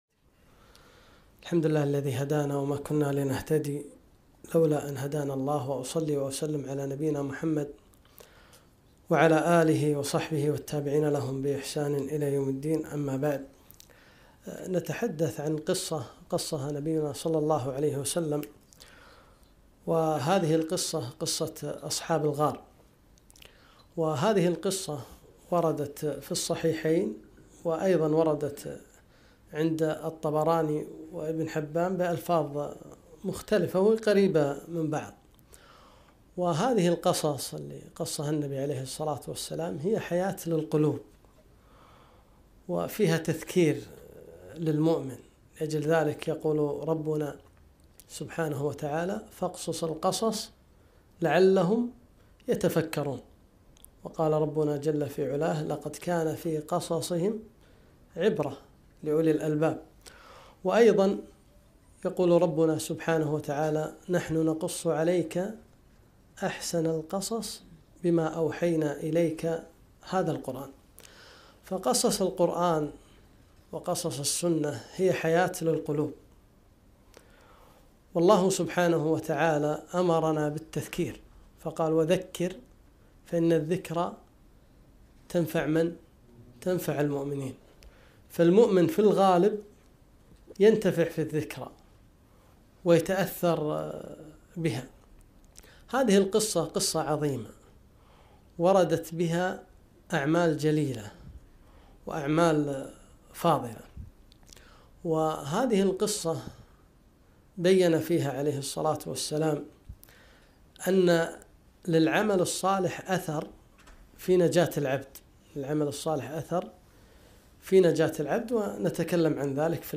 محاضرة - تأملات في قصة أصحاب الغار